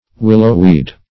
Search Result for " willow-weed" : The Collaborative International Dictionary of English v.0.48: Willow-weed \Wil"low-weed`\, n. (Bot.)